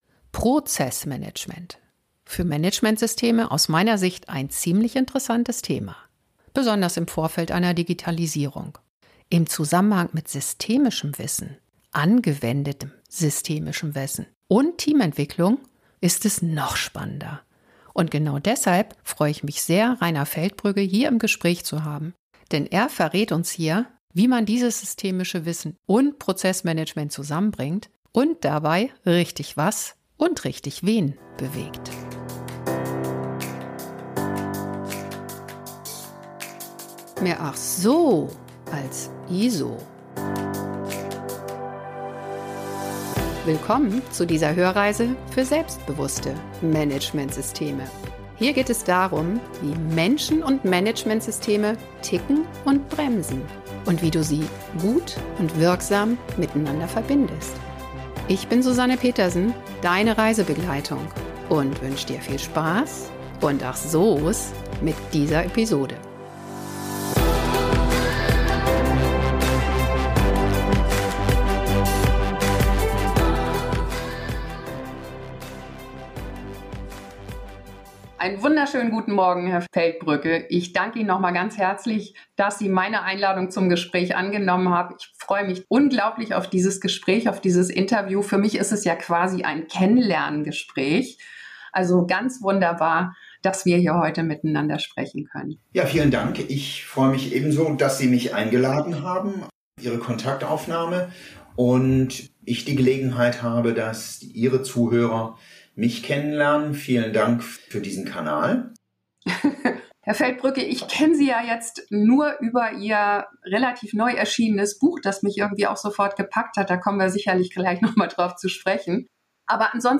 Im Gespräch beleuchtet und interpretiert er nicht nur seine reichhaltigen Erfahrungen.